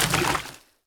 etfx_explosion_liquid.wav